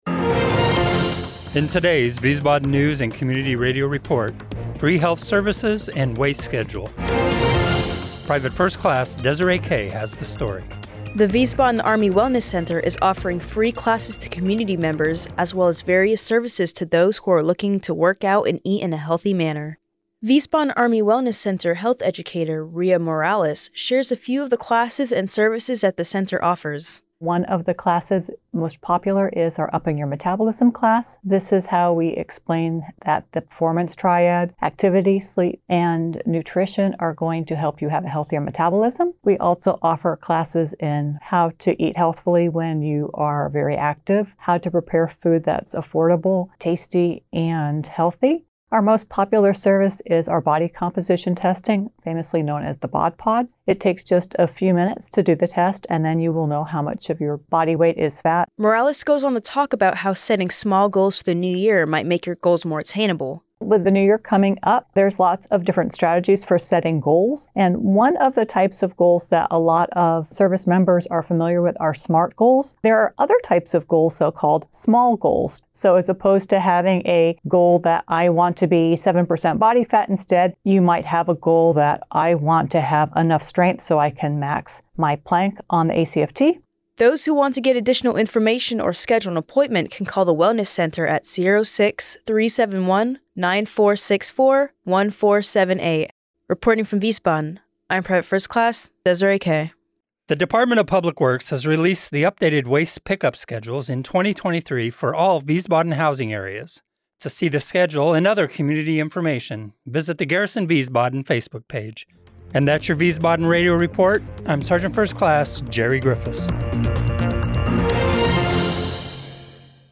The audio news was recorded in the AFN Wiesbaden studio on Clay Kaserne, Wiesbaden, Hessen, DE, Dec. 29, 2022.